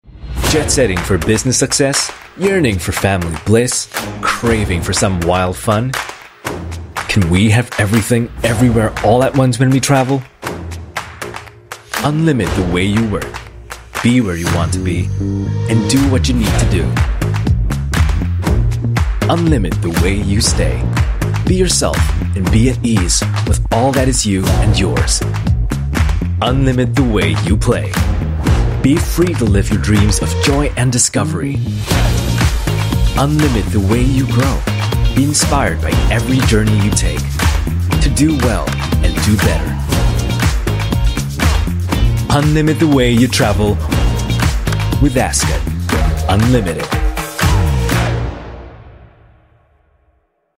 EN Asian
male